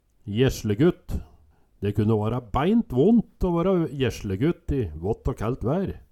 jeslegutt - Numedalsmål (en-US)